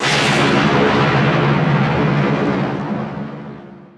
Thunder03.wav